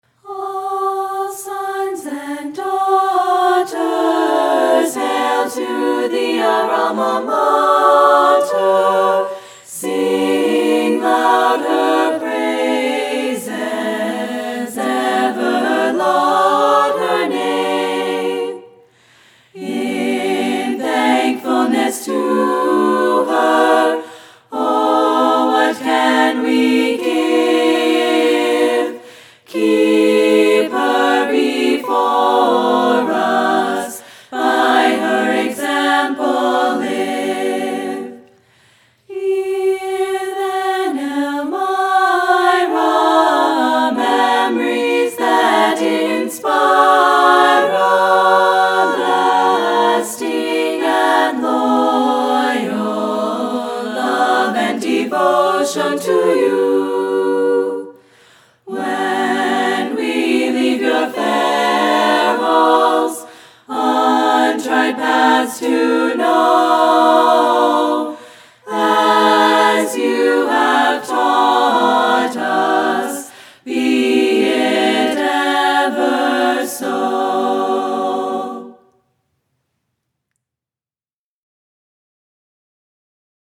Click on the "Listen (MP3)" button to hear the song performed by the EC Chiclettes where available.